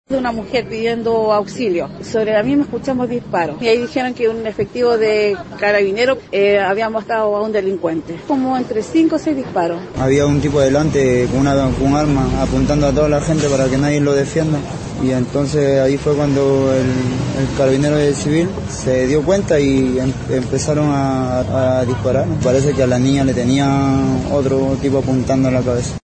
Testigos del incidente respaldaron la acción del policía y confirmaron que la mujer fue apuntada en la cabeza con un arma de fuego.
350-cuna-asalto-testigos.mp3